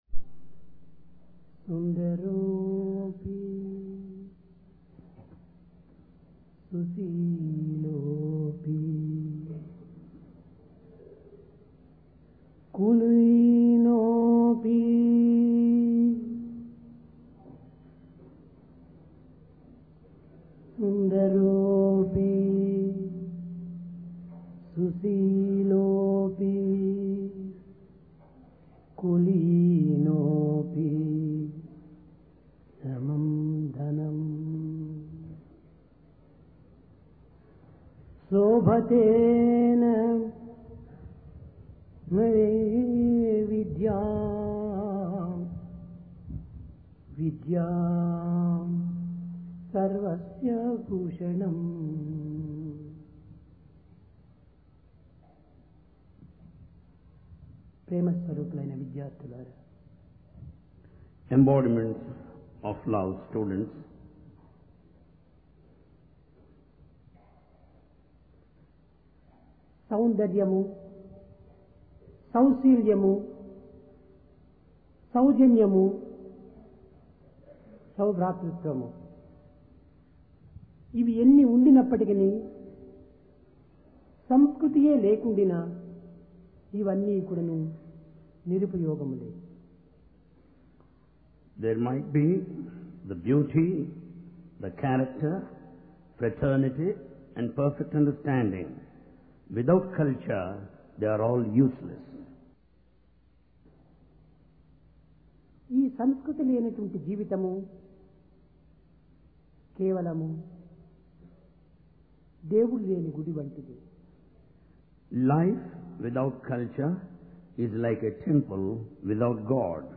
Sai Darshan Home Date: 16 Jun 1996 Occasion: Divine Discourse Place: Prashanti Nilayam The Spiritual Heritage Of Bharath Life without culture is like a temple without God.